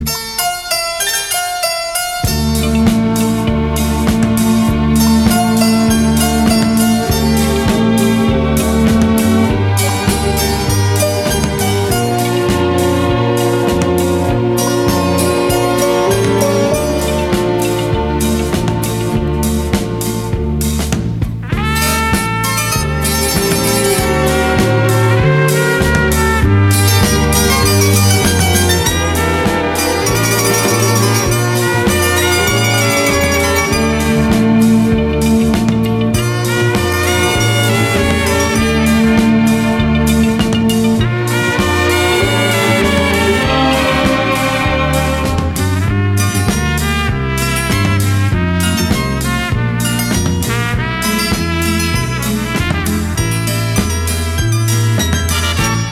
Рингтоны из фильмов